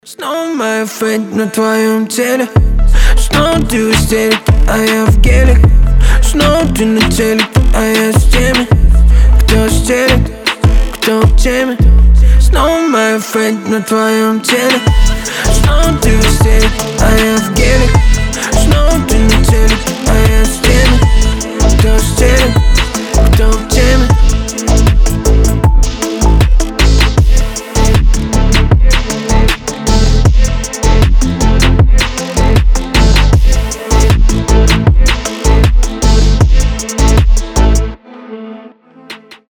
• Качество: 320, Stereo
басы